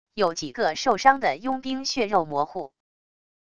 有几个受伤的佣兵血肉模糊wav音频生成系统WAV Audio Player